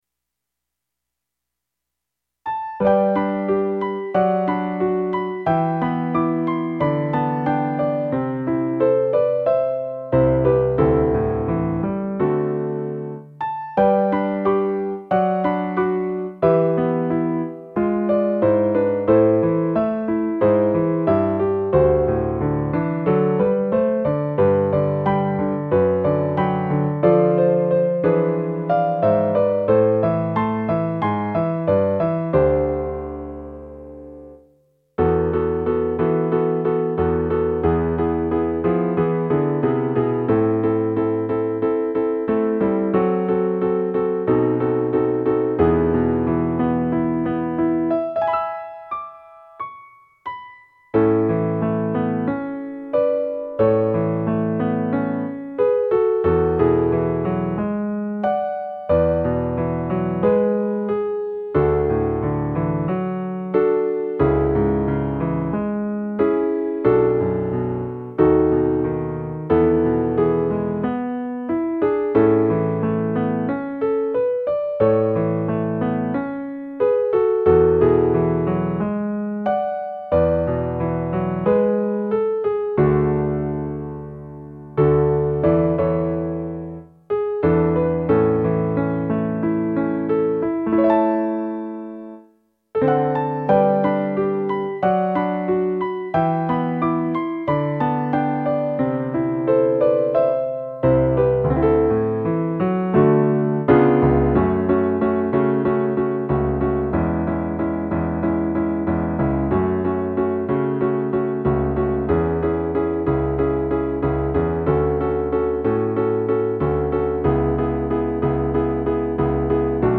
ピアノ伴奏「サクラソウのうた（MIDI音源）」
Sakurasou-Piano.mp3